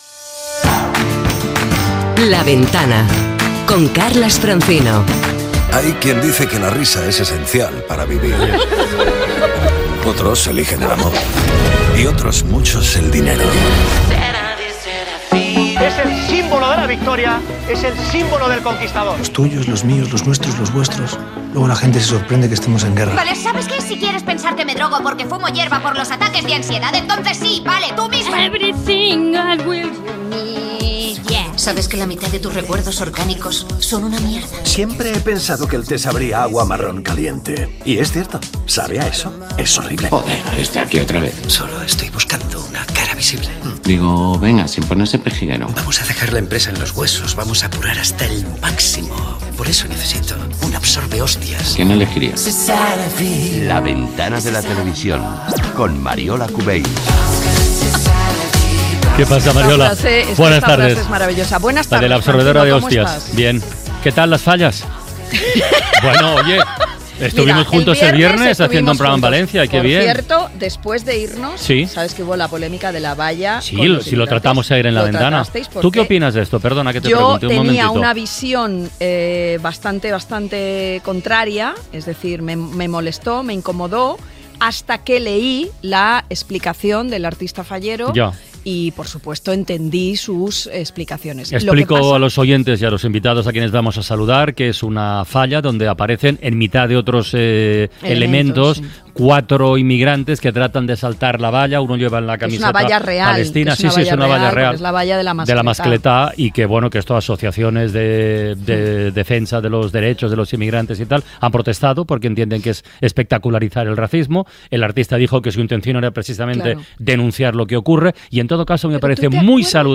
Careta de la secció, diàleg inicial sobre les Falles de València.
Entrevista
Entreteniment